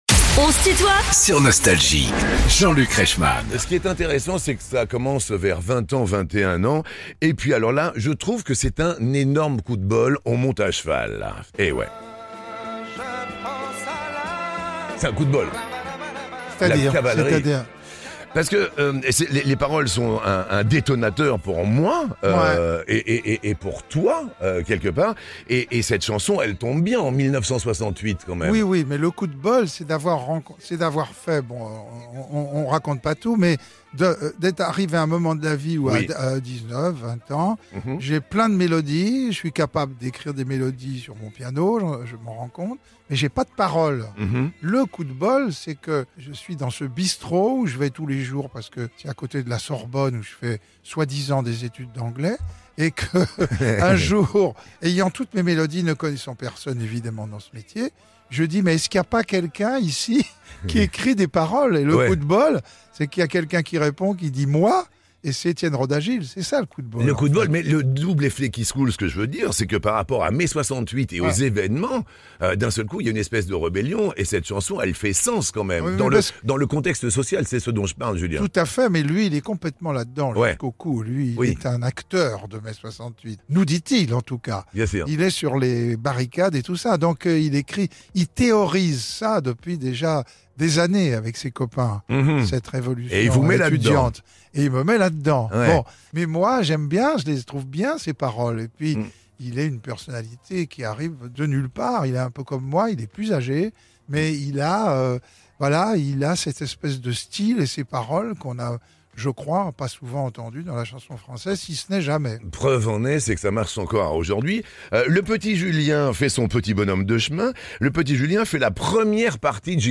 Julien Clerc est l'invité de "On se tutoie ?..." avec Jean-Luc Reichmann